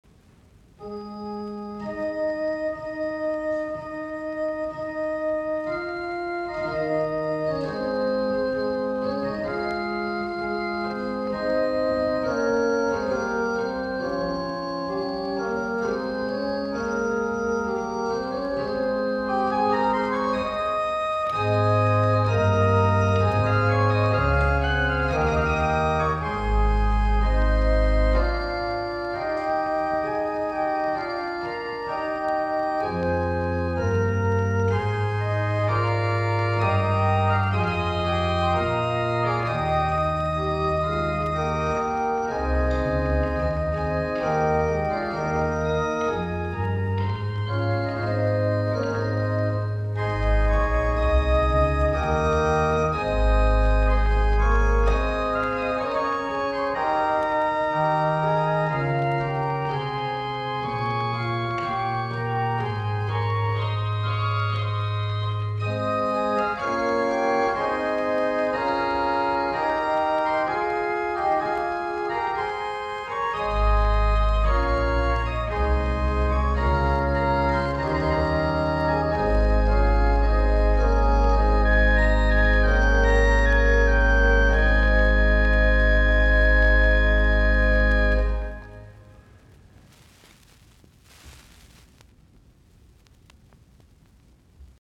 musiikkiäänite
Soitinnus: Urut.
Frederiksborg, Slotkirke, Hillerød.